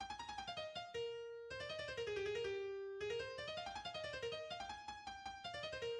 Effectif Orchestre symphonique
4. Allegro, en mi bémol majeur, à
Début de l'Allegro final: